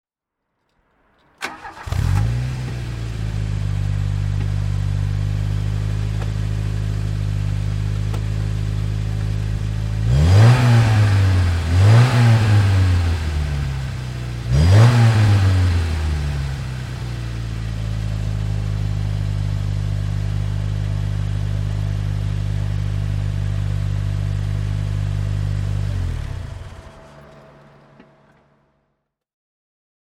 VW Golf LS (1976) - Starten und Leerlauf
VW_Golf_LS_1976.mp3